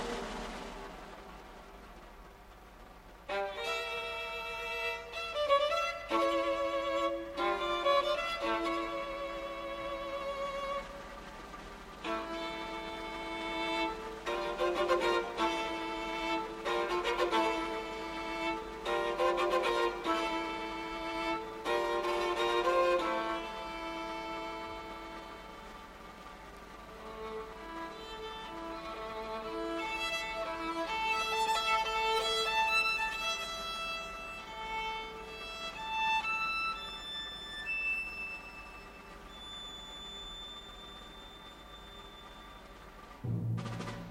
Violin: Rimsky-Korsakov: Capriccio Espagnol Mvt. IV (Concertmaster Solo) – Orchestra Excerpts